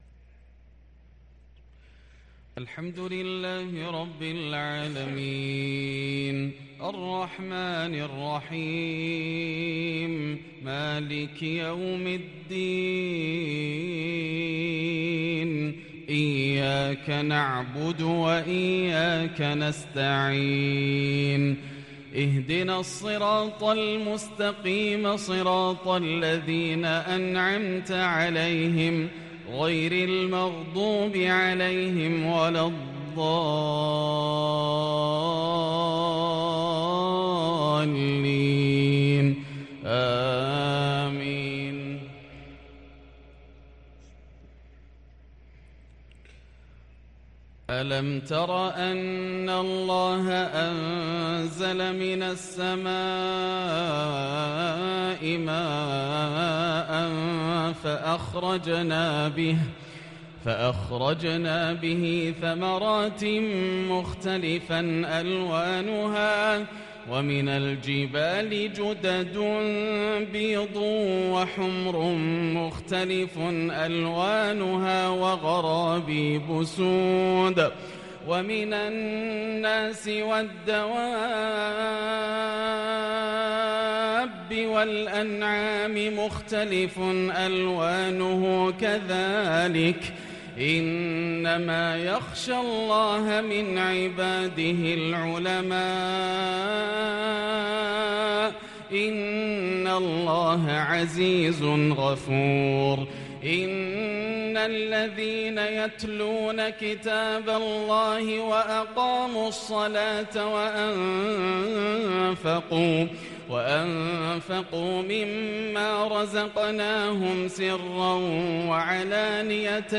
عشاء الخميس 5-2-1444هـ من سورة فاطر | Isha prayer from Surat Fatir 1-9-2022 > 1444 🕋 > الفروض - تلاوات الحرمين